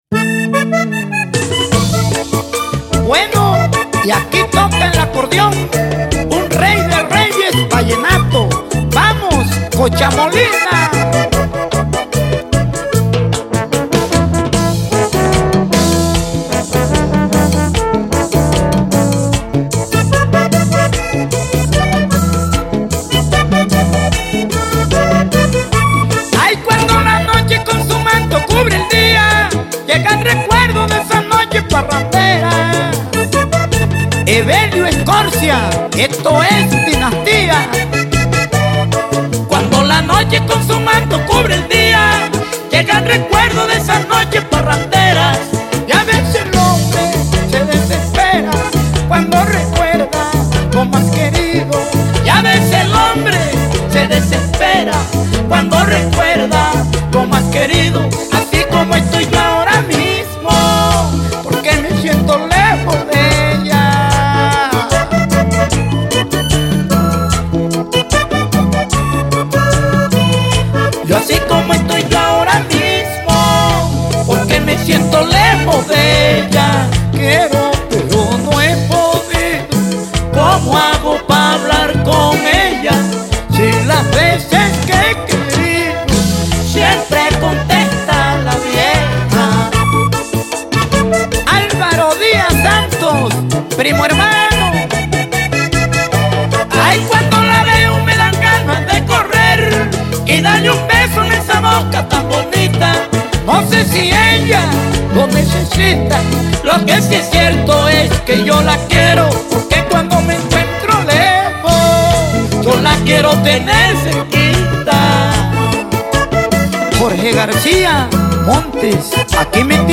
El cd contó con la participación de seis acordeoneros